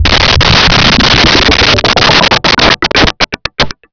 scifi8.wav